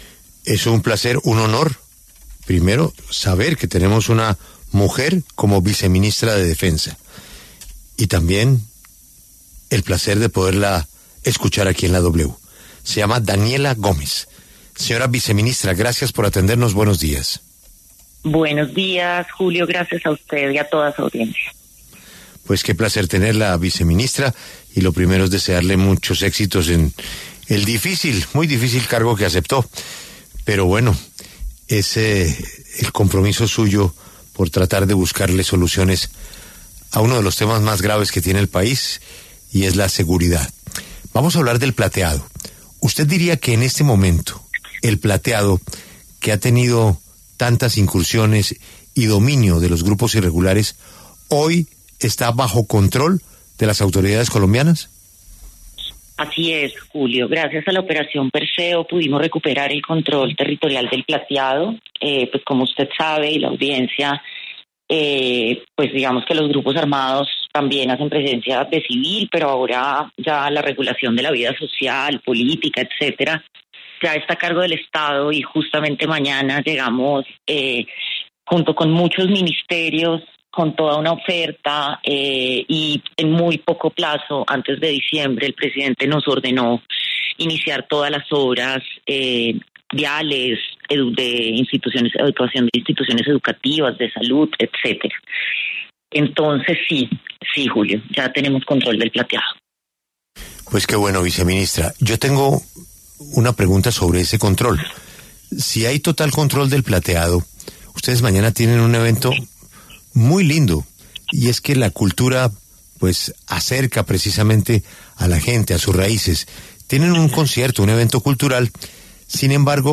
En La W, la viceministra de Defensa Daniela Gómez se refirió a las amenazas de las disidencias de las Farc contra varios artistas que iban a presentarse en el corregimiento de El Plateado.